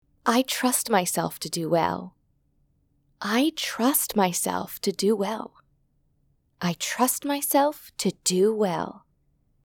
Affirmation performance
1. Saying affirmations three times, each time emphasizing a different part of the affirmation.